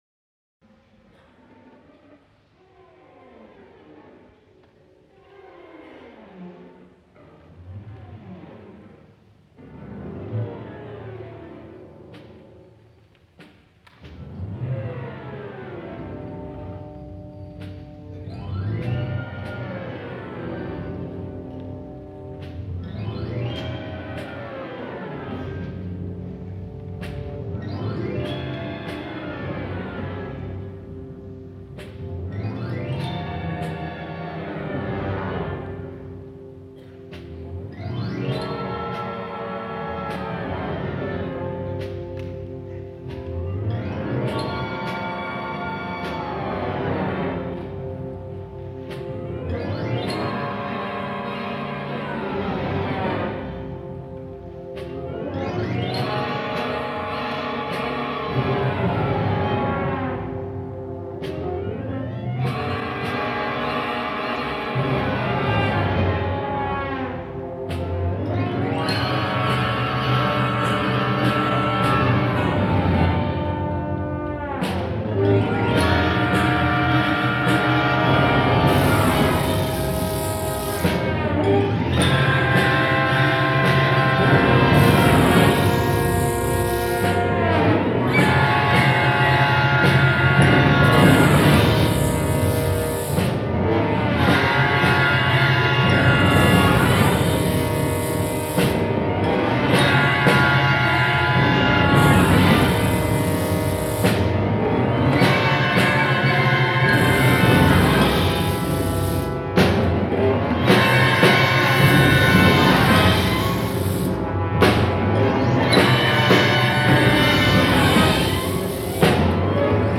Concierto Sinfónico